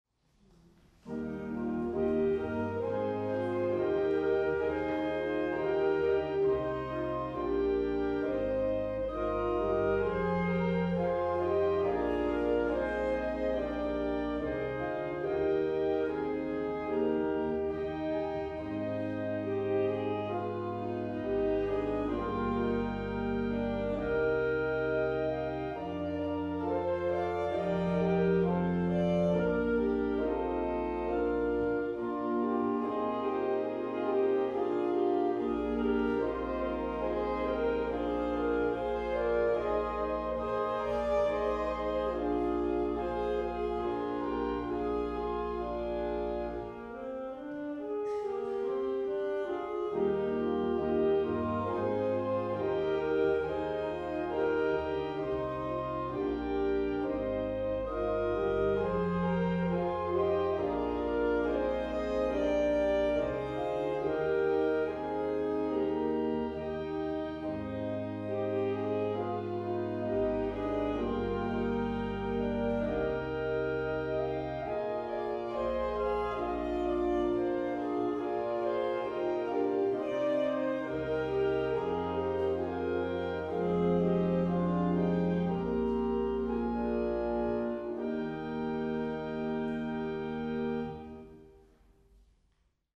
Saxophonistinnen
bevölkerten am 6. Mai die Orgelempore der Martin-Luther-Kirche
Der weiche Ton ihrer Instrumente verband sich bestens mit dem
Ausschnitte aus dem Konzert: